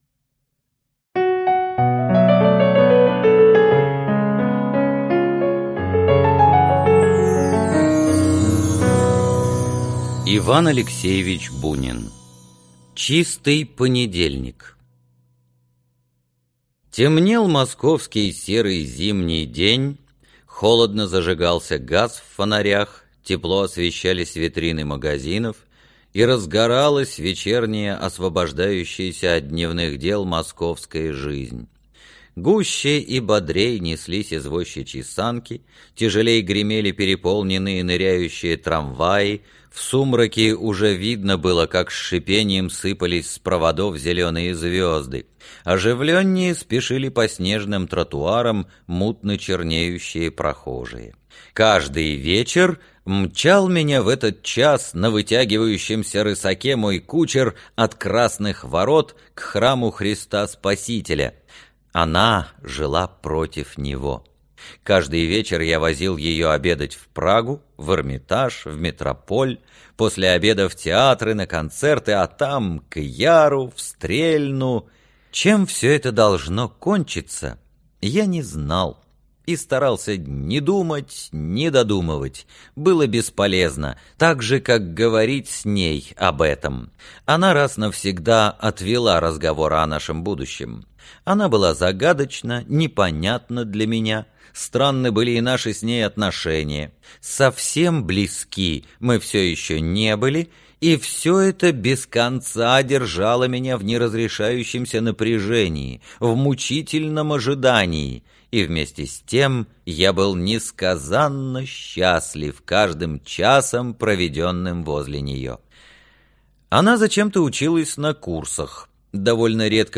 Аудиокнига Чистый понедельник | Библиотека аудиокниг